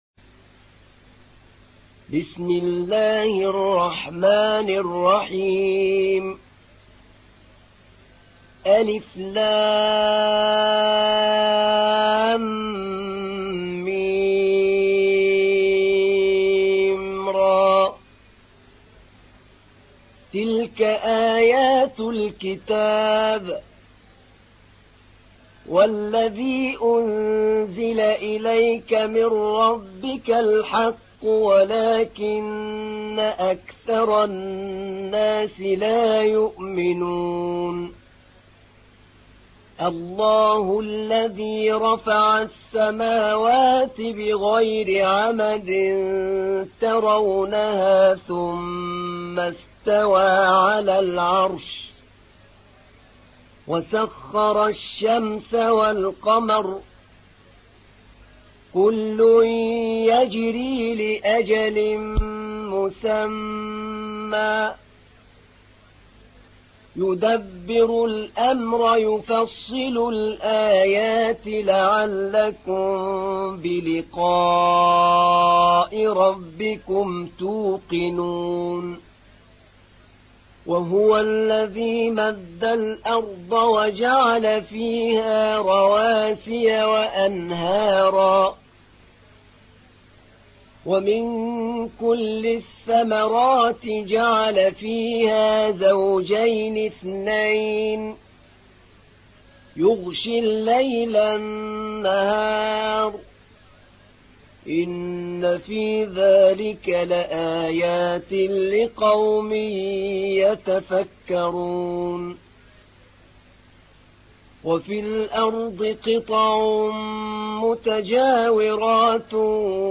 تحميل : 13. سورة الرعد / القارئ أحمد نعينع / القرآن الكريم / موقع يا حسين